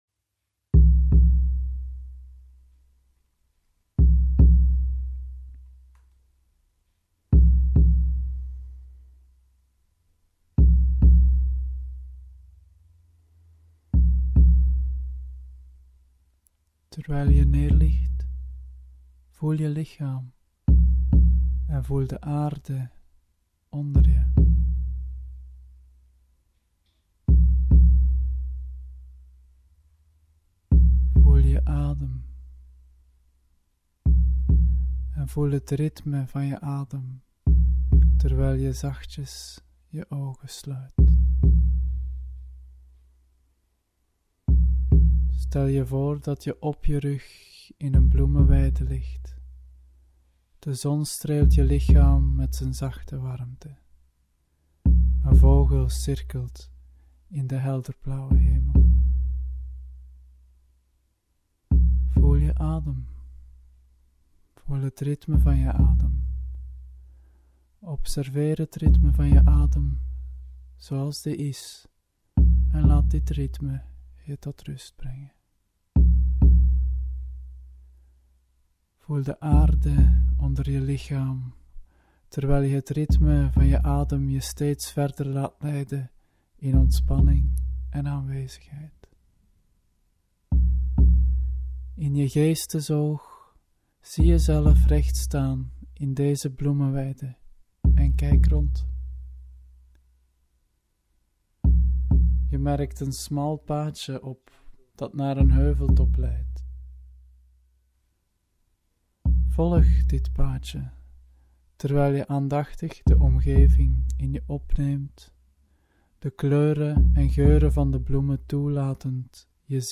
Sjamanistische meditatie in Merudi Forum
Voor de mensen die graag eens een geleide meditatie doen.